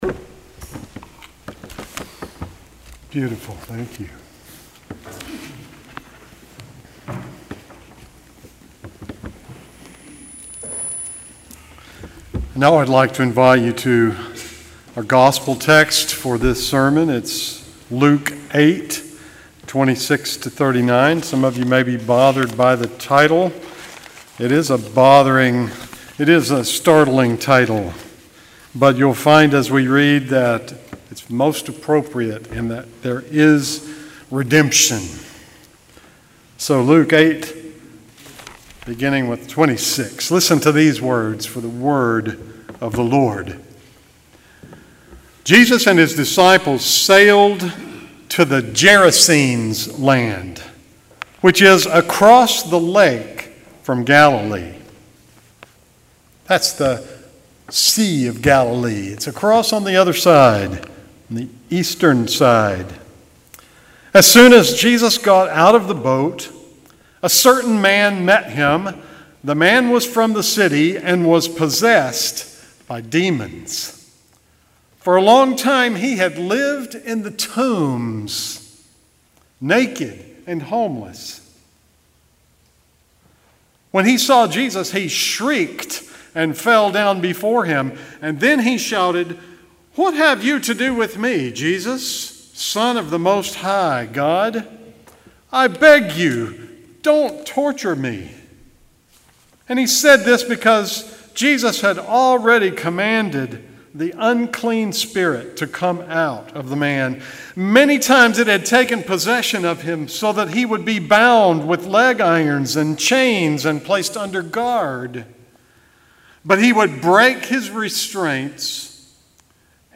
June 23, 2019 Sermon
Traditional Sermon